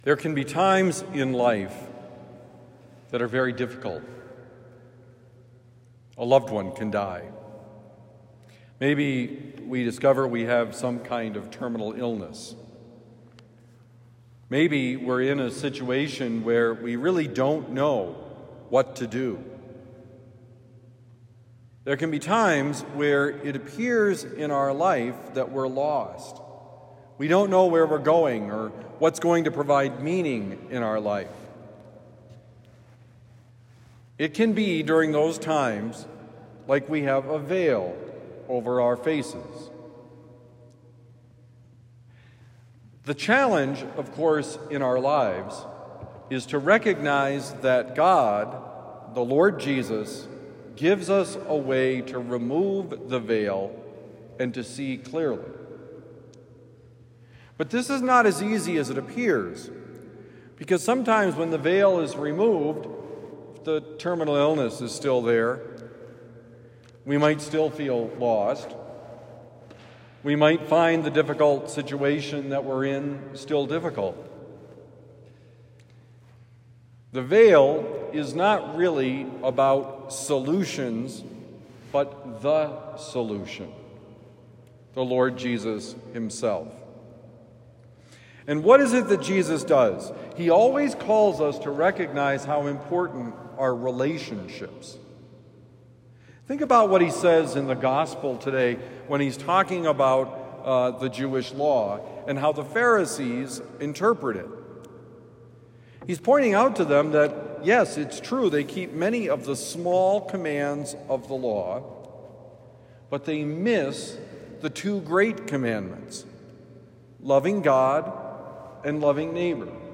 See clearly with Jesus: Homily for Thursday, June 12, 2025